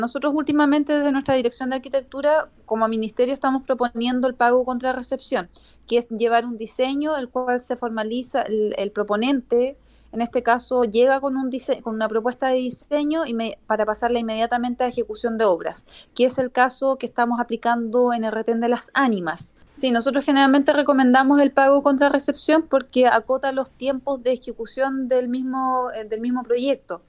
La seremi de Obras Públicas, Nuvia Peralta, afirmó que están esperando que el Gobierno Regional obtenga la recomendación satisfactoria (RS) del Ministerio de Desarrollo Social para iniciar el proceso licitatorio.